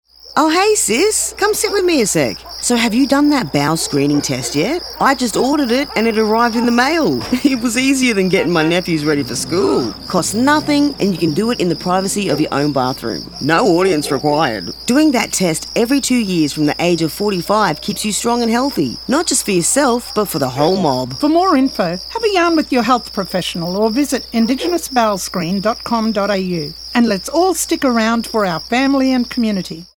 Using humour, pride in local culture, and Creole translations, we’ve created an engaging and accessible campaign.
Whether it’s sharing the importance of bowel cancer screening or reminding listeners about their health check-ups, we’ve made sure the tone reflects the spirit of the community—inviting, approachable, and relatable.